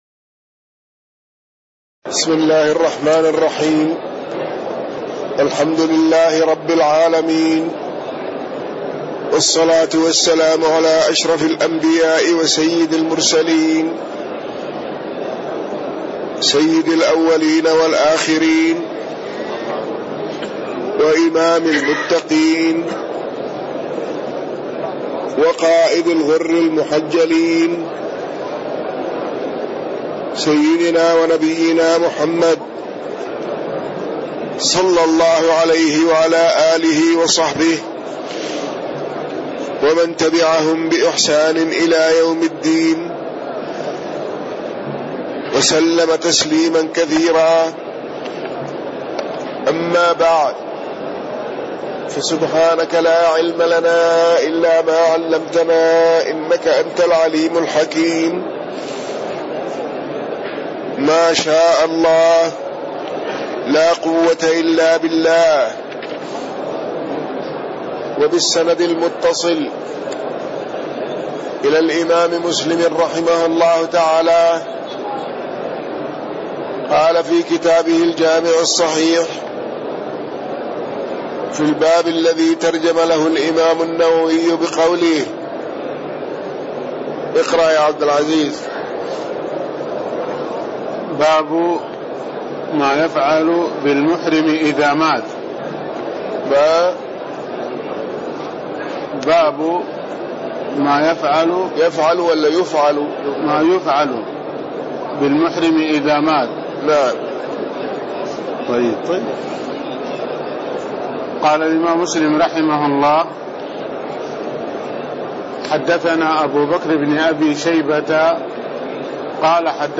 تاريخ النشر ١٩ محرم ١٤٣٣ هـ المكان: المسجد النبوي الشيخ